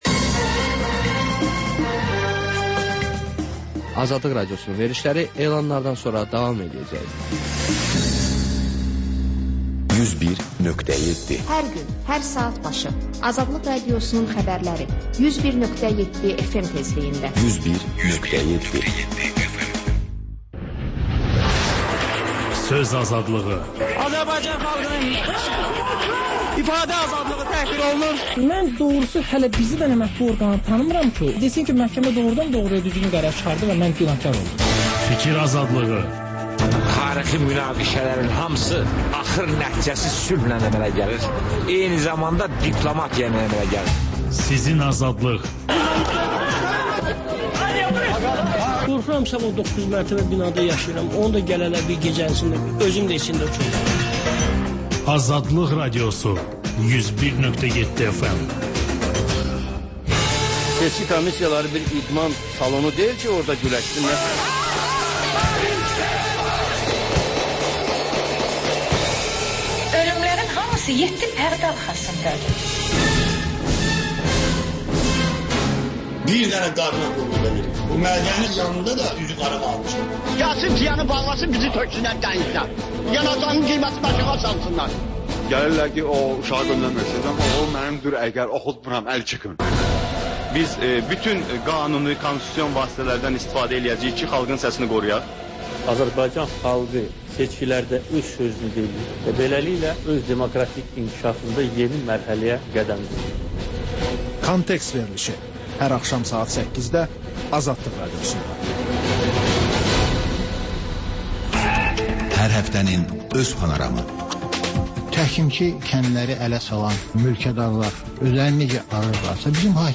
Xəbərlər, sonra CAN BAKI: Bakının ictimai və mədəni yaşamı, düşüncə və əyləncə həyatı… Həftə boyu efirə getmiş CAN BAKI radioşoularında ən maraqlı məqamlardan hazırlanmış xüsusi buraxılış